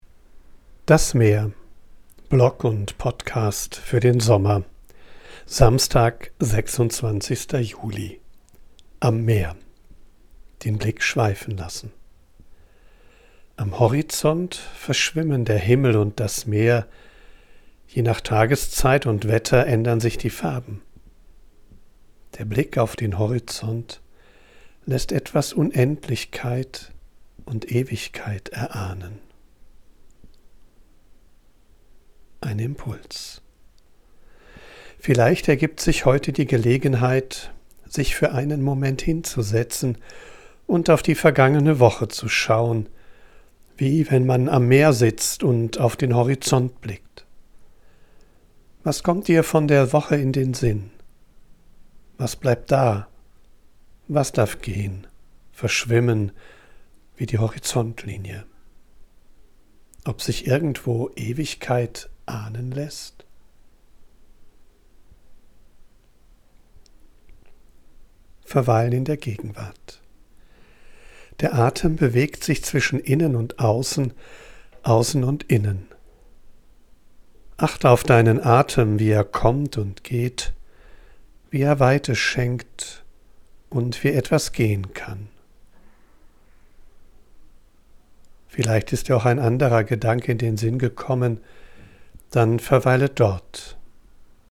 live. Ich bin am Meer und sammle Eindrücke und Ideen. Weil ich
von unterwegs aufnehme, ist die Audioqualität begrenzt. Dafür
mischt sie mitunter eine echte Möwe und Meeresrauschen in die